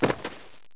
BODYFAL2.mp3